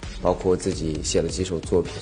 Chinese_Audio_Resource / 蔡徐坤 /有背景音乐的声音 /包括自己写了几首作品.wav